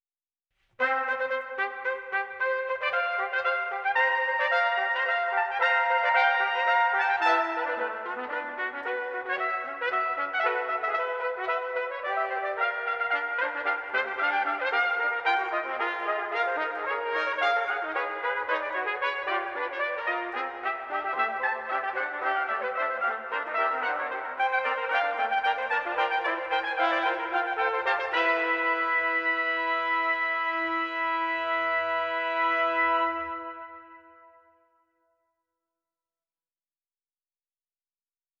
The project will demonstrate the various harmonic, melodic, rhythmic and emotional contributions of the selected composers through interpretation of their music on the trumpet.